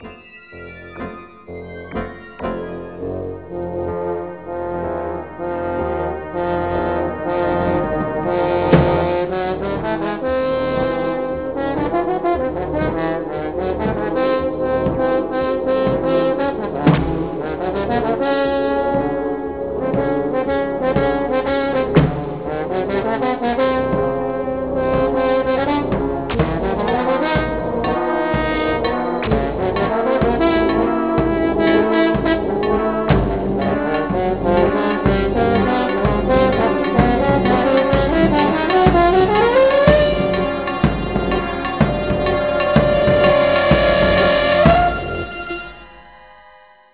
contemporary works for horn(s)
horn